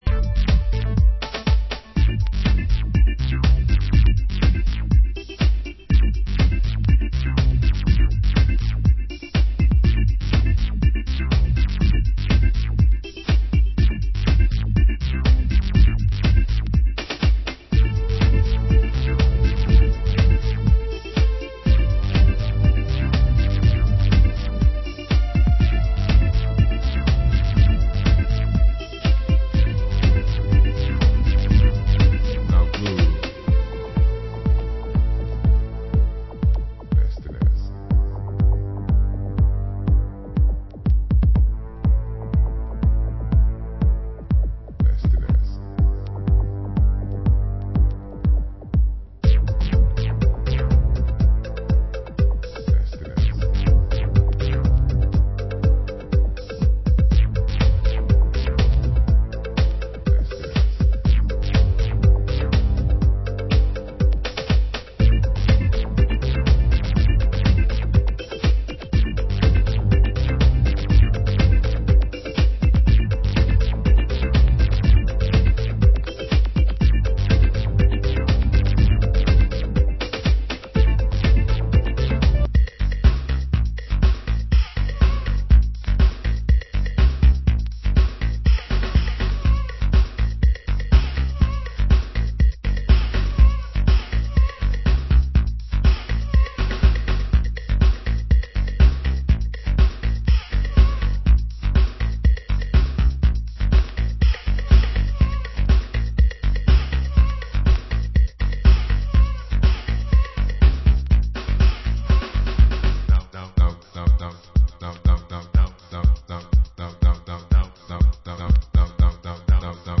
Genre: Chicago House